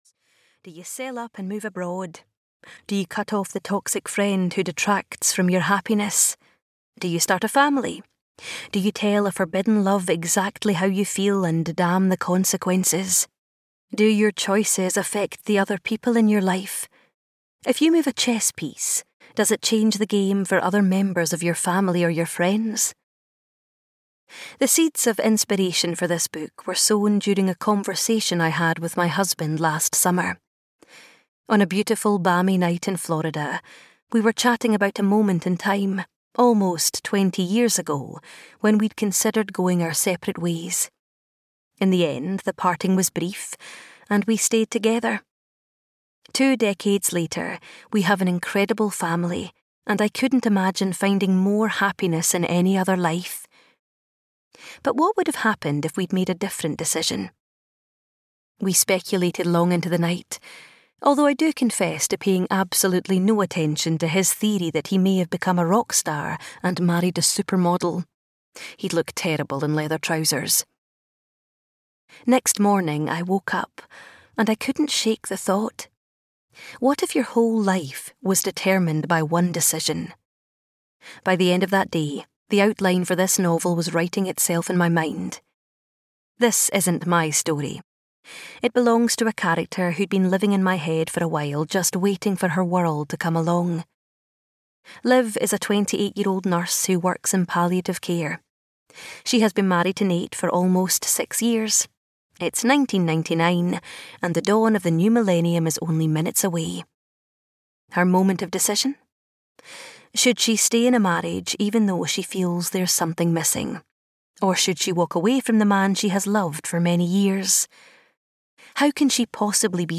With or Without You (EN) audiokniha
Ukázka z knihy